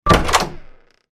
DoorsDoorlabledopen.mp3